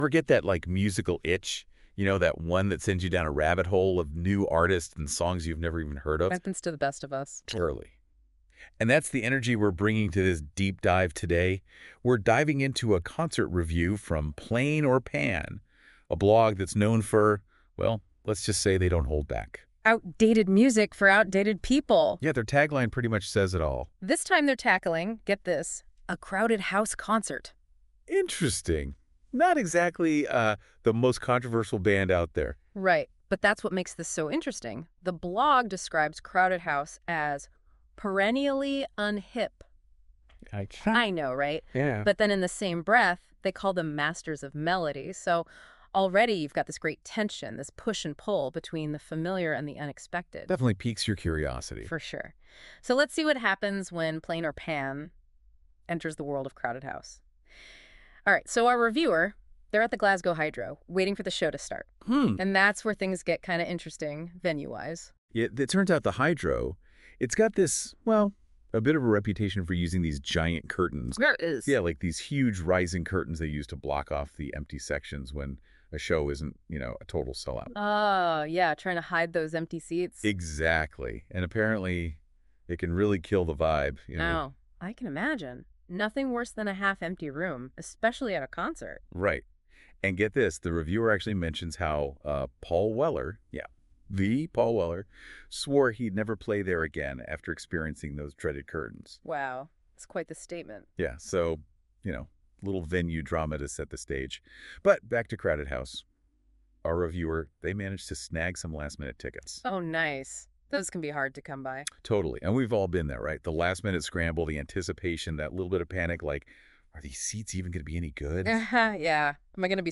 I woke up last week to a version of the Crowded House post I’d written, presented as a podcast between two chummy American chat show hosts. It was, frankly, mindblowing in the way it’d taken my original post, summed up the gist of it and spat it back at me as a two-way conversation in third person.